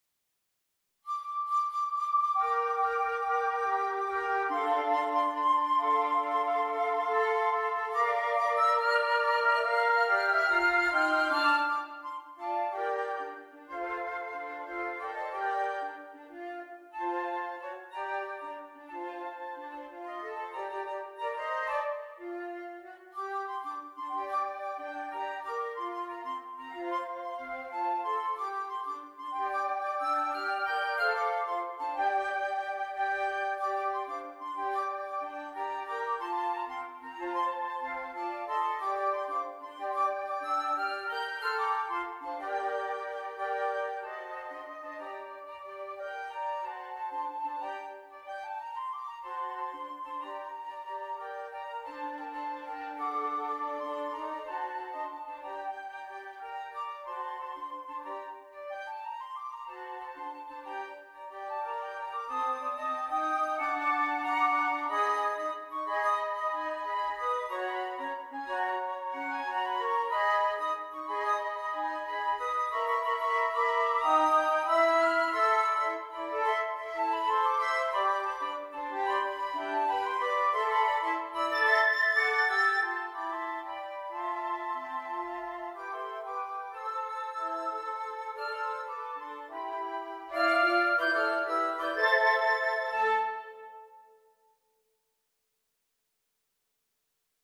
A rhythmic jazz arrangement of well-known Sea Shanty
for Flute Quartet with optional Alto Flute part.
Jazz and Blues